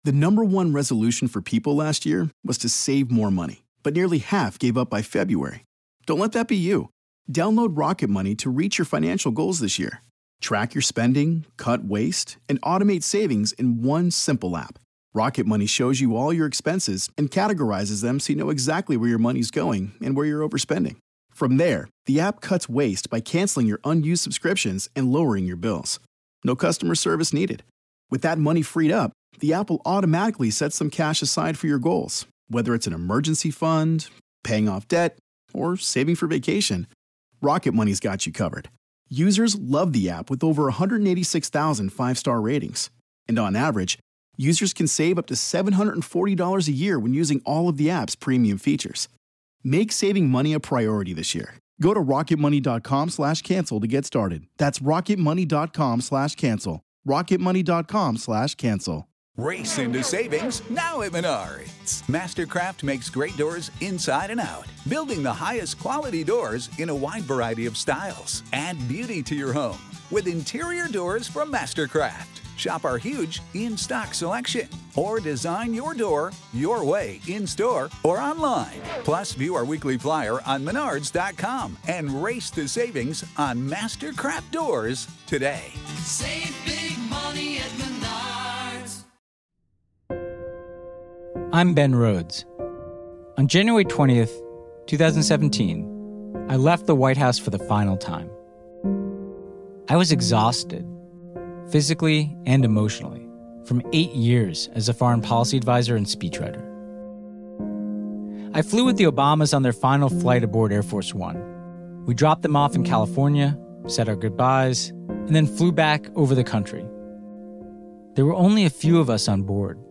Host Ben Rhodes talks to Susan Rice, Samantha Power, and progressive activists abroad, about the successes, failures, and ultimately the necessity of U.S. leadership.